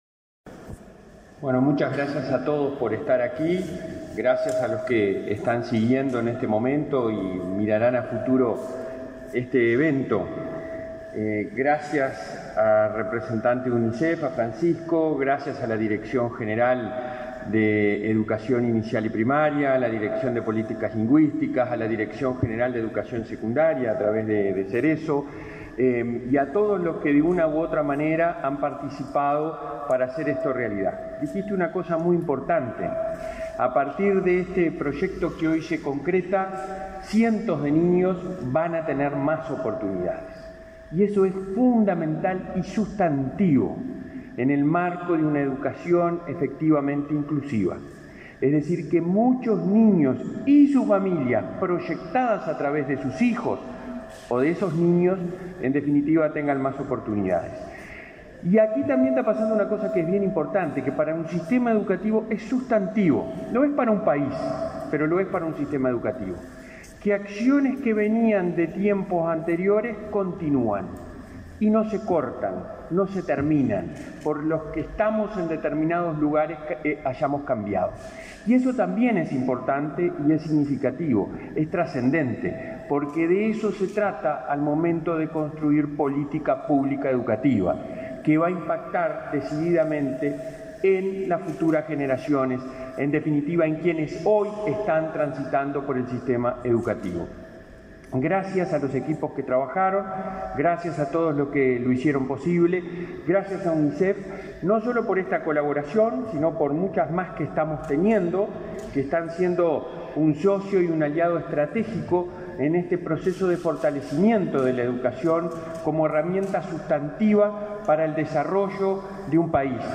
Palabras del presidente de ANEP, Robert Silva